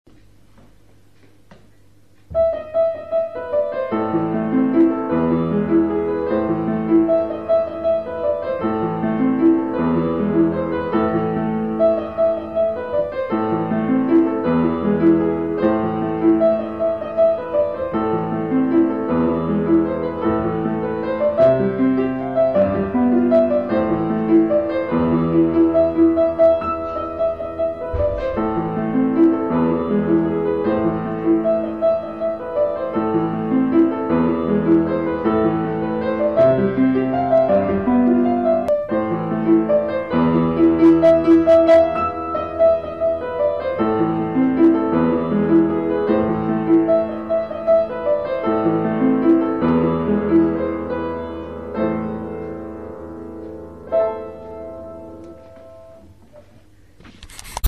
רק החלק הראשון של היצירה
גדלתי על המוזיקה קלאסית..
והביצוע-פשוט אבל יפה..
לקראת הסוף היה פיקשוש קל. עצרת לאיזה  חלקיק שניה (לא כחלק מהיצירה!).